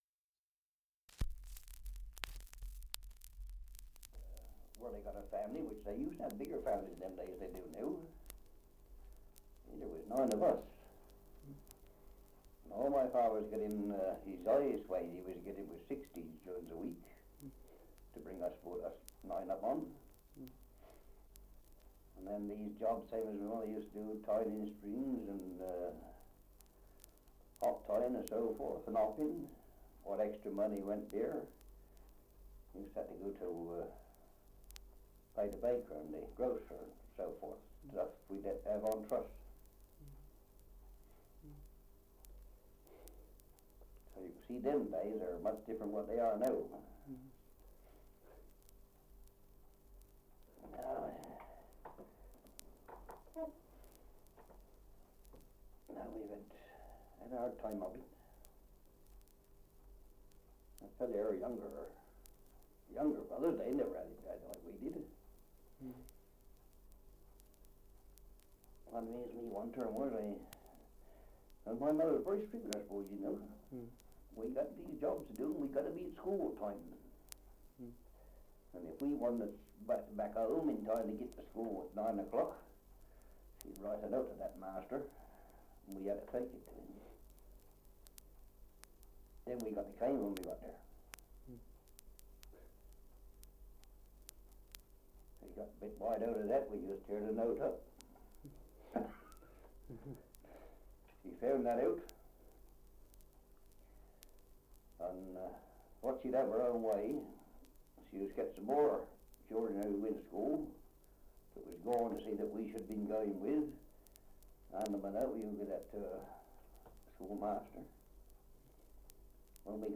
2 - Survey of English Dialects recording in Goudhurst, Kent
78 r.p.m., cellulose nitrate on aluminium